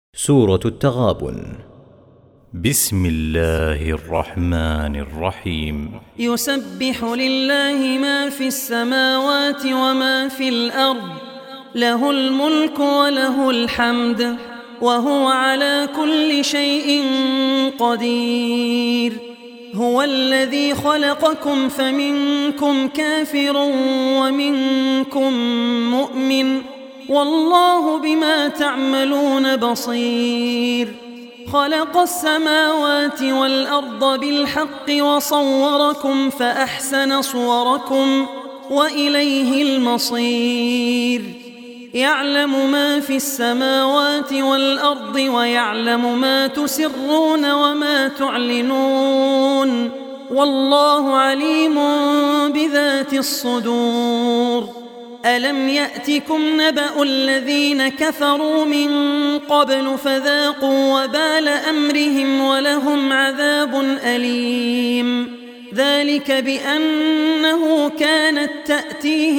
Surah al-Taghabun arabic recitation mp3.